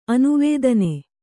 ♪ anuvēdane